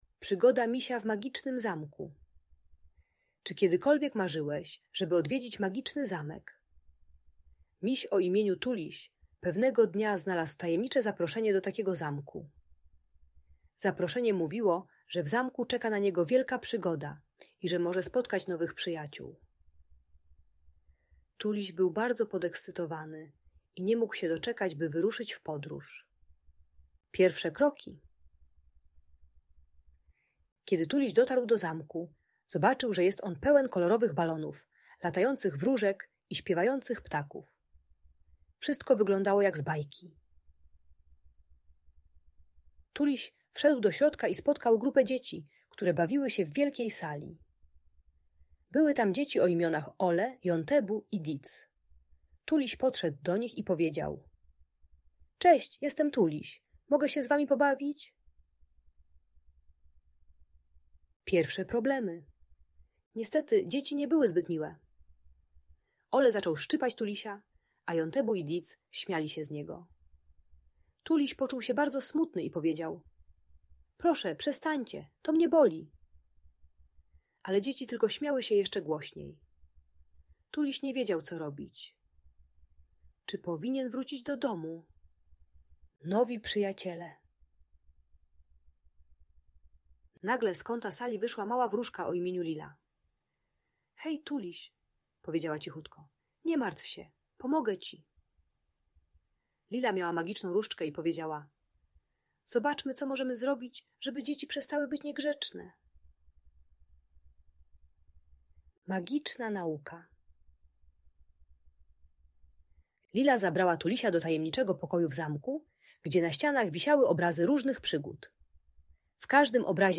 Przygoda Misia Tulisia w Magicznym Zamku - Audiobajka